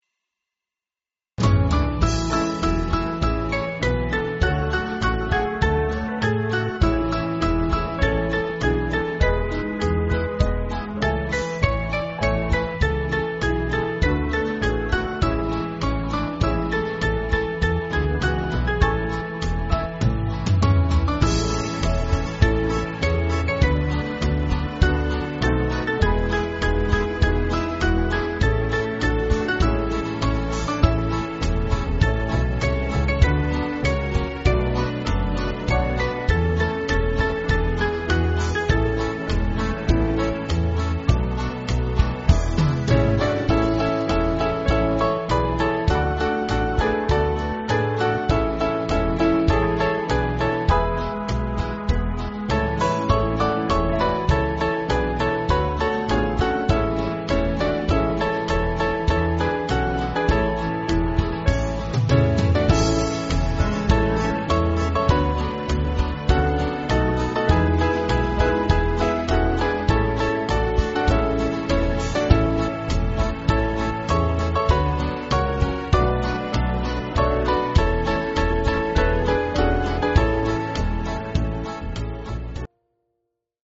Small Band
(CM)   4/A-Bb